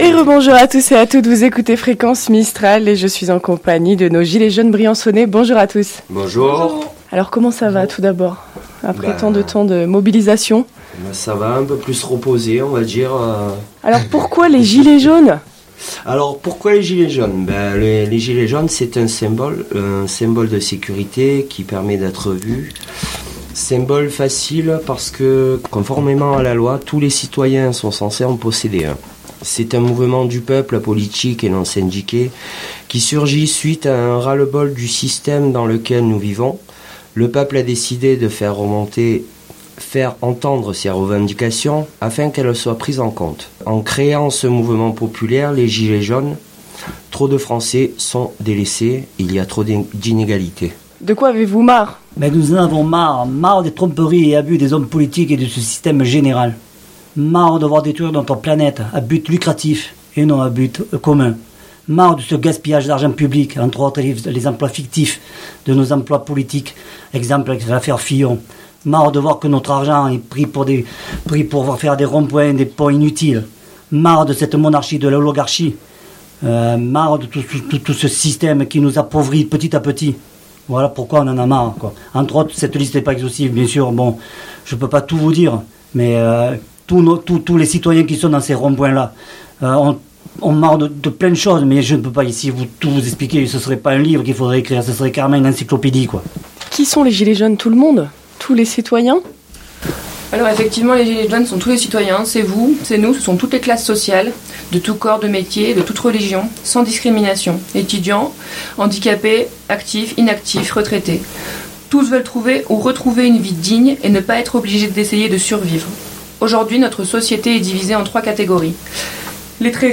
Ce matin nous avons le plaisir d’accueillir dans notre studio 3 Gilets Jaunes Briançonnais pour revenir sur ses derniers temps et s’exprimer sur la suite du mouvement.